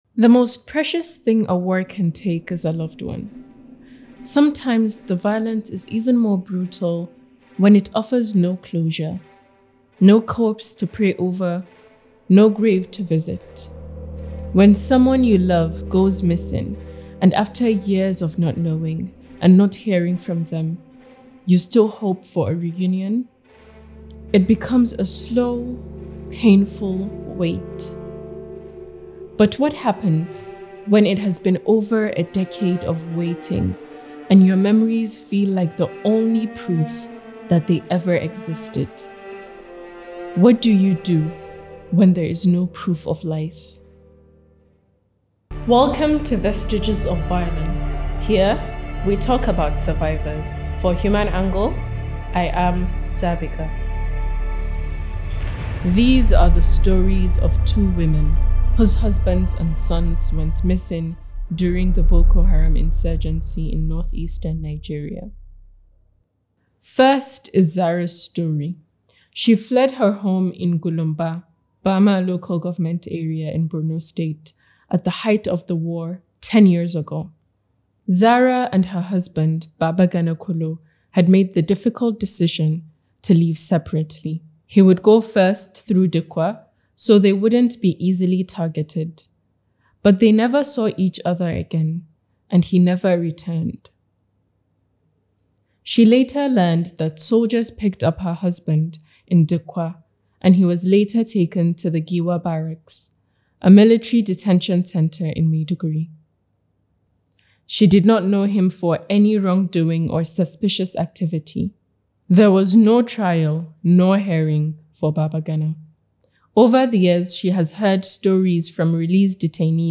Voice acting